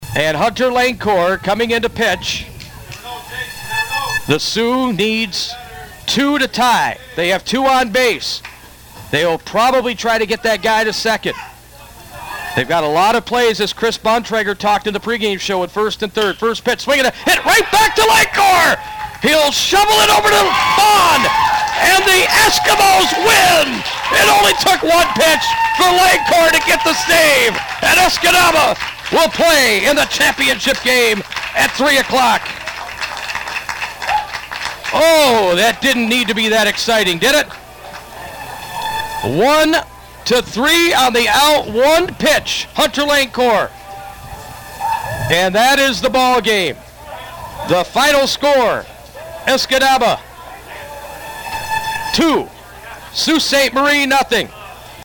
Radio Call